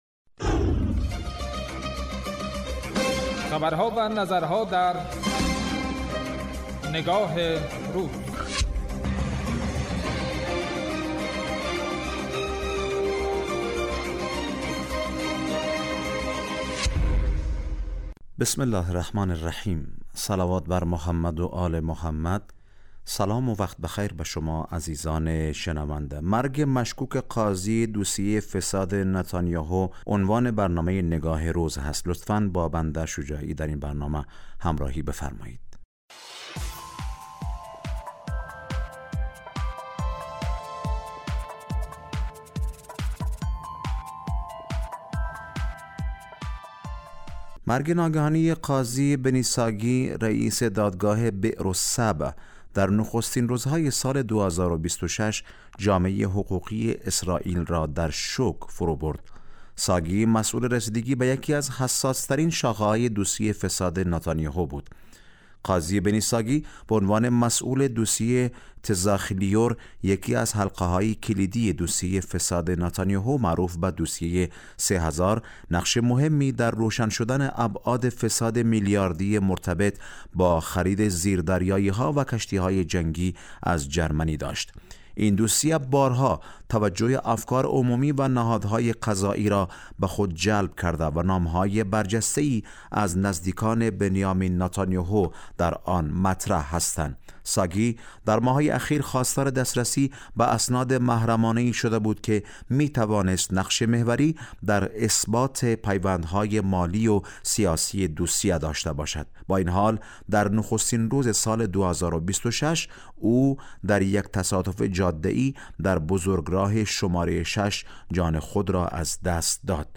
برنامه تحلیلی نگاه روز